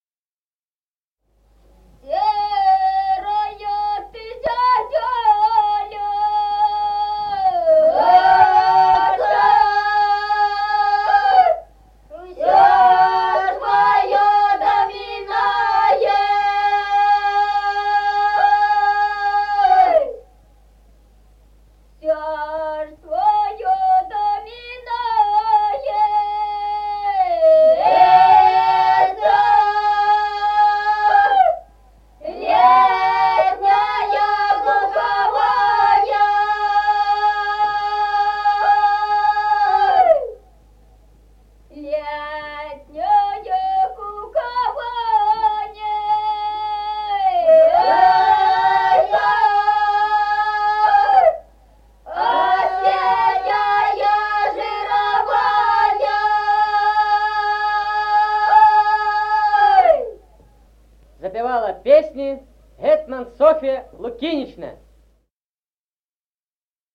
Музыкальный фольклор села Мишковка «Серая ты зязюлечка», жнивная.